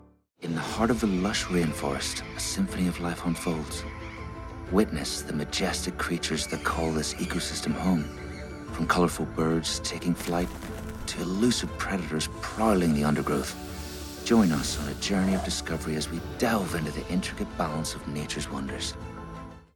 20s-40s. Male. Northern Irish.
Narration